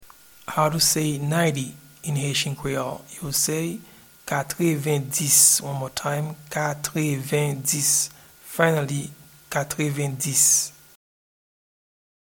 Pronunciation and Transcript:
Ninety-in-Haitian-Creole-Katrevendis.mp3